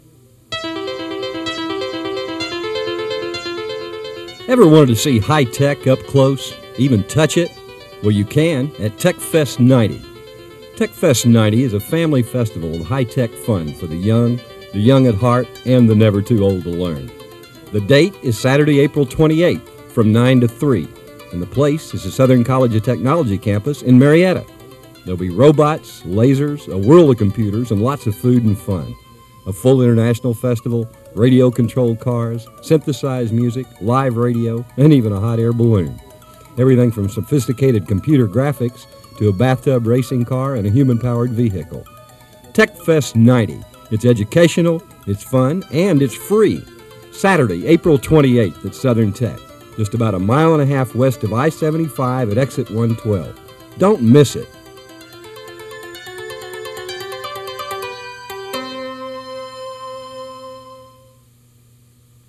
TechFest radio advertisement, 1990
Radio ad for TechFest 1990 at the Southern College of Technology.
techfest-radio-ad-1990.mp3